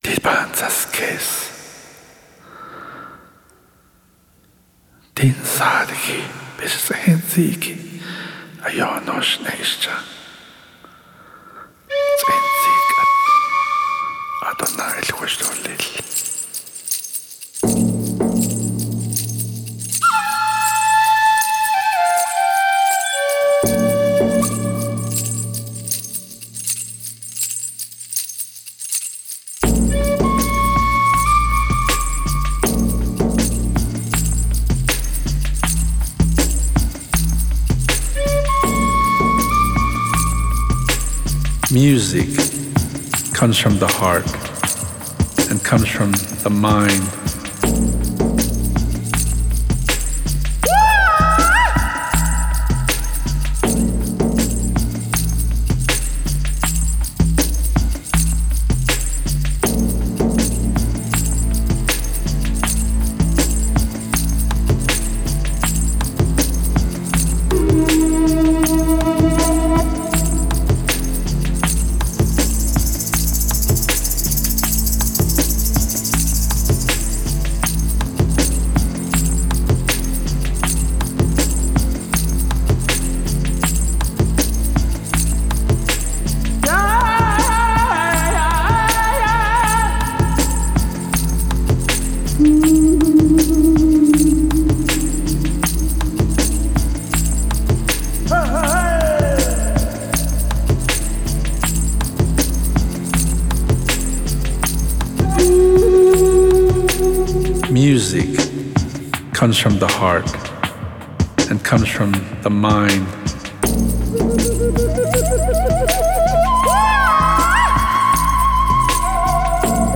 Genre: Native American.